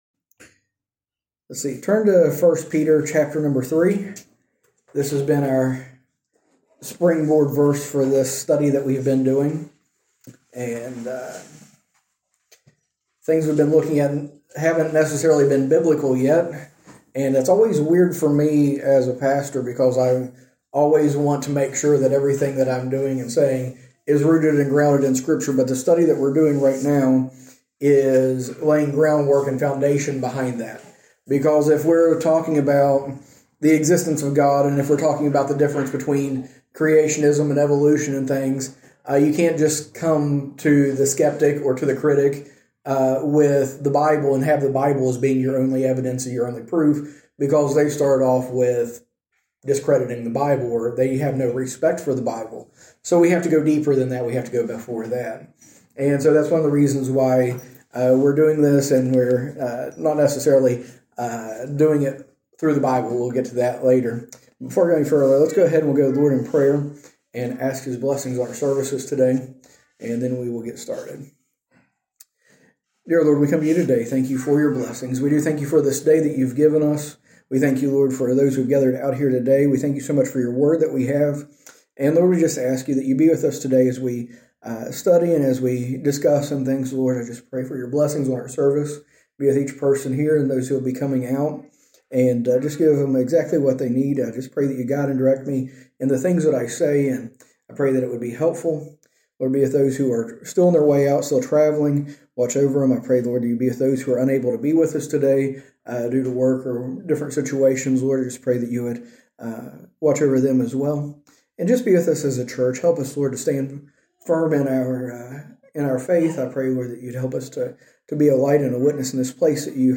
A message from the series "A Reasonable Faith."